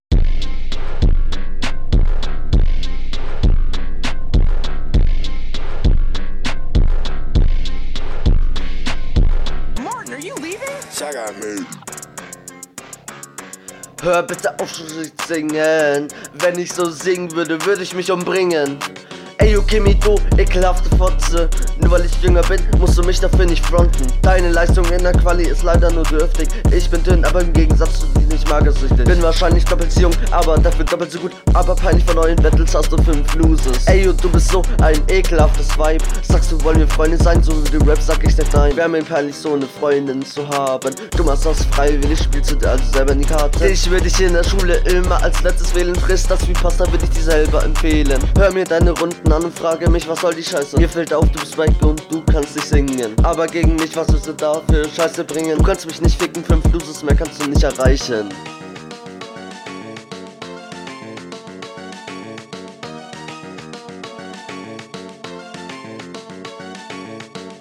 Flow: Wirkt noch angestrengt und eckig. Versuchst irgendwie auf dem Beat zu bleiben.
Beat find ich nice.